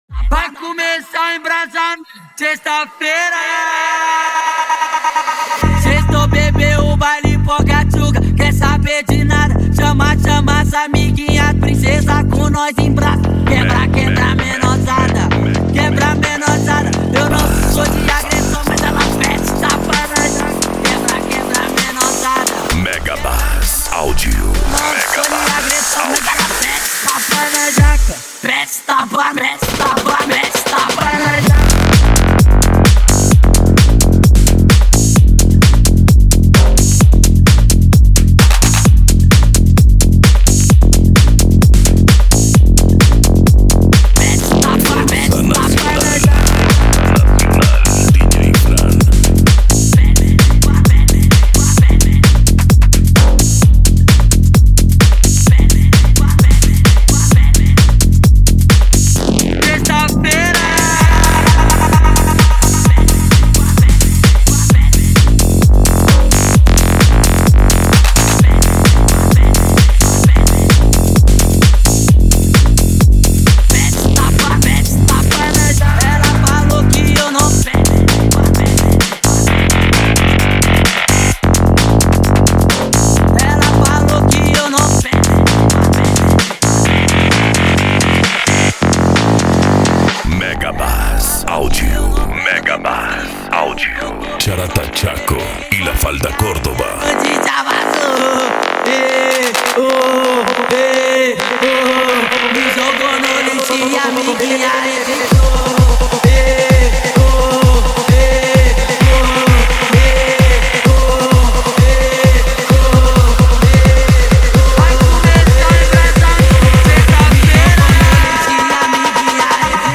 Bass
Funk
Mega Funk
Minimal